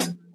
Closed Hats
bed_hat.wav